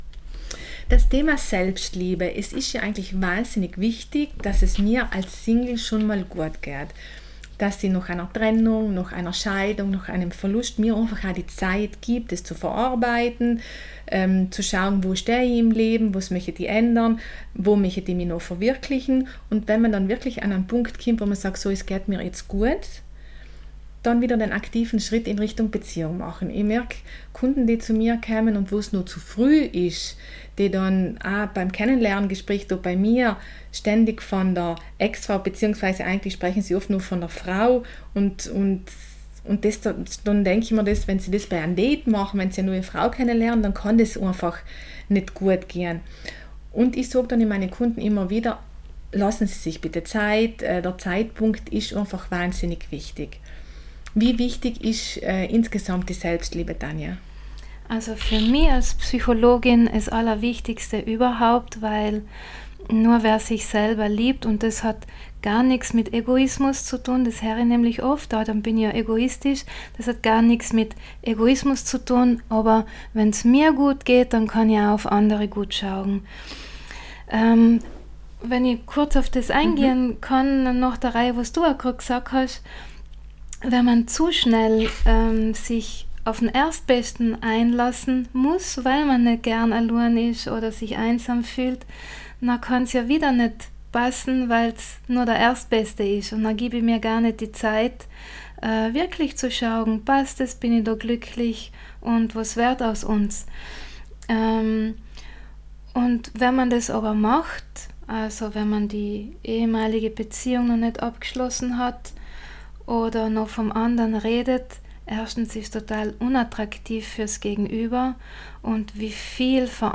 Im Interview mit Psychologin